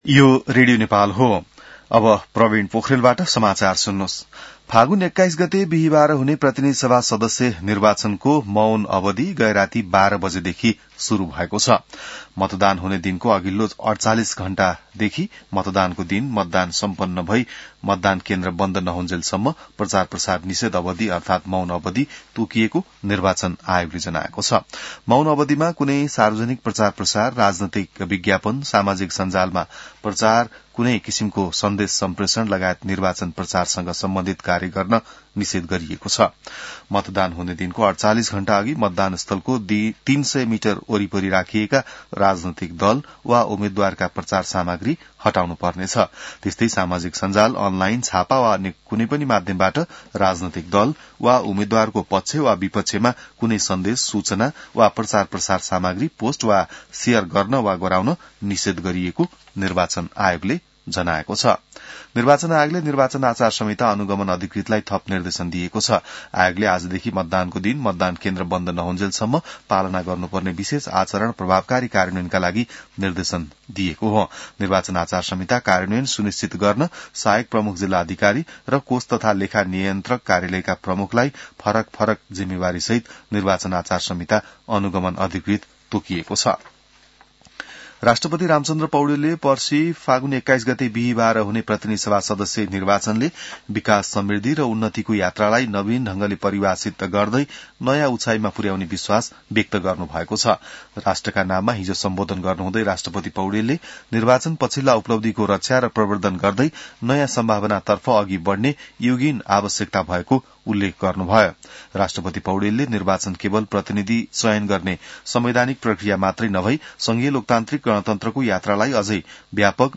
An online outlet of Nepal's national radio broadcaster
बिहान ६ बजेको नेपाली समाचार : १९ फागुन , २०८२